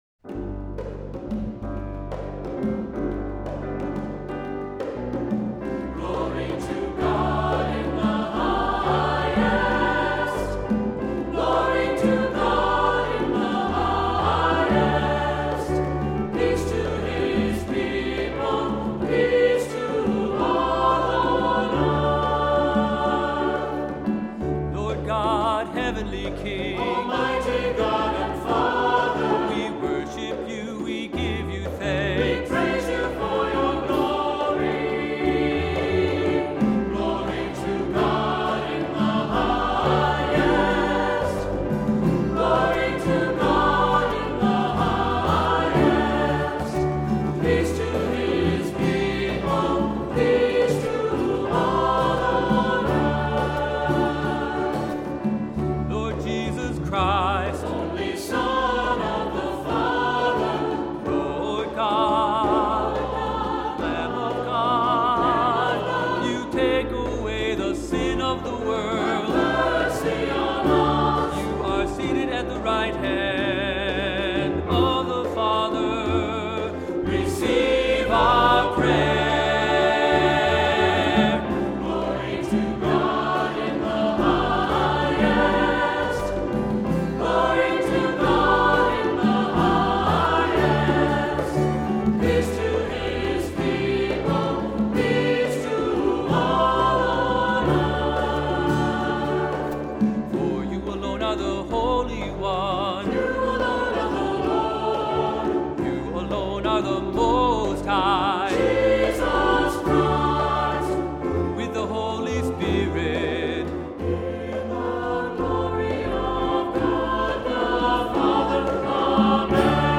Voicing: SAB; Cantor; Assembly